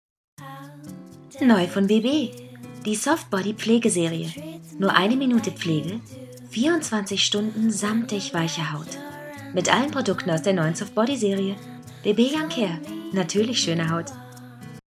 Sprechprobe: Werbung (Muttersprache):
Actress, Dubbing, Advertisement, Games, Audio Drama, Voice-Over, Native Speaker (German), English (US), Warm, Feminine, Sensitive, Clear, Laid-Back, Cool, Young, Fresh, Hip, Charming, Seductive, Provoking, Challenging, Dark, Mad, Angry, Wicked, Emotional, Understatement, Comical, Funny, Comic-Voice, Playful